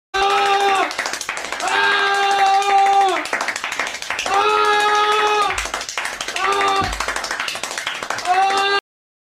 Long Lungs Fart Téléchargement d'Effet Sonore
Long Lungs Fart Bouton sonore